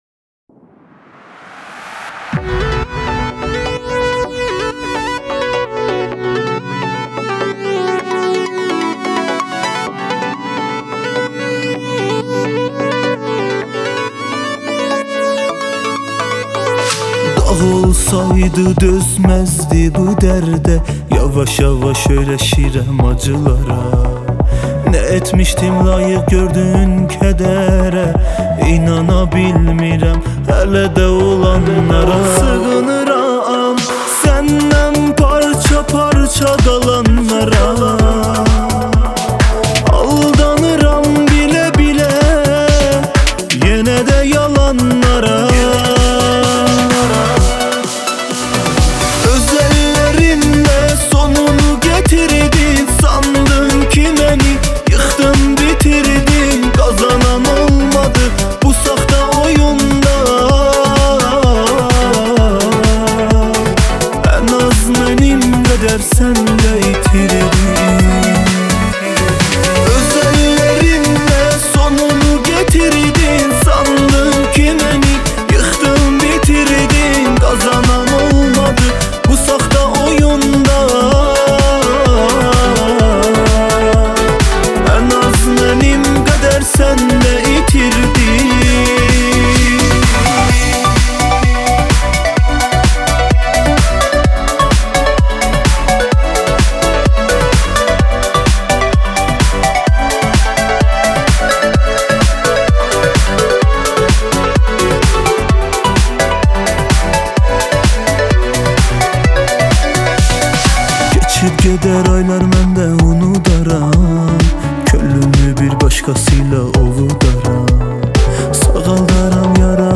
دسته بندی : دانلود آهنگ ترکی تاریخ : جمعه 18 اکتبر 2019